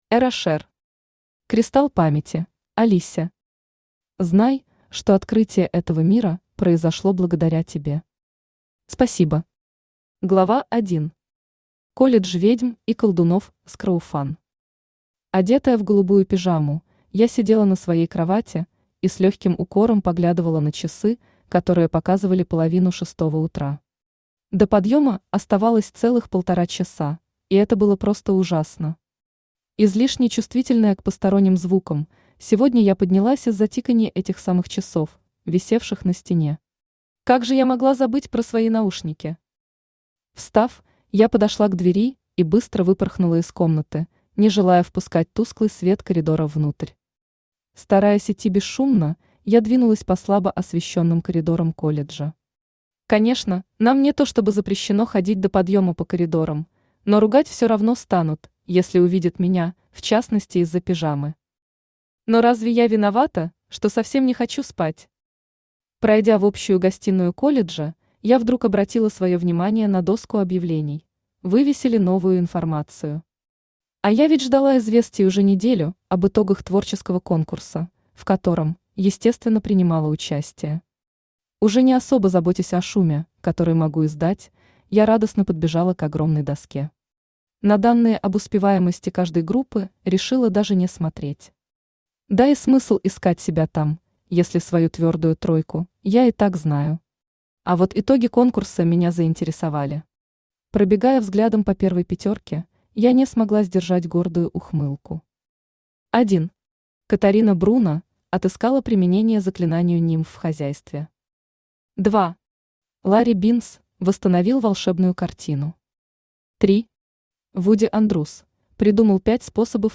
Аудиокнига Кристалл памяти | Библиотека аудиокниг
Aудиокнига Кристалл памяти Автор Эра Шер Читает аудиокнигу Авточтец ЛитРес.